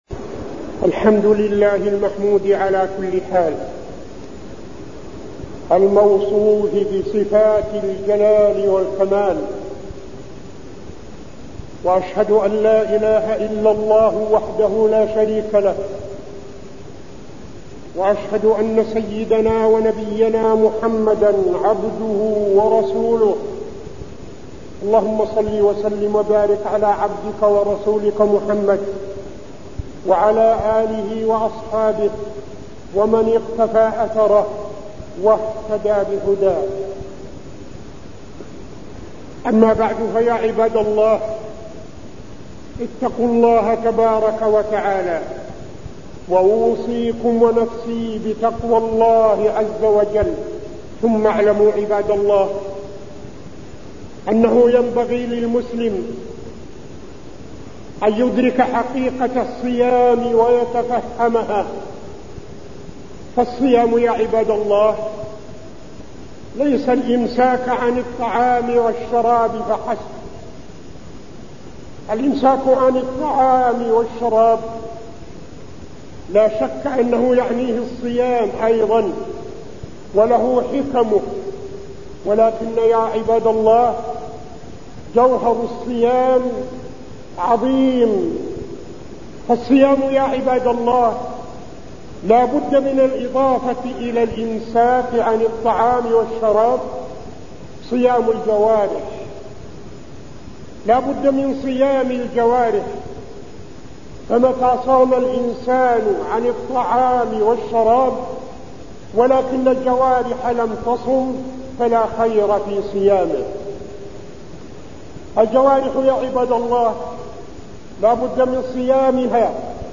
تاريخ النشر ١٣ رمضان ١٤٠٨ هـ المكان: المسجد النبوي الشيخ: فضيلة الشيخ عبدالعزيز بن صالح فضيلة الشيخ عبدالعزيز بن صالح حقيقة الصيام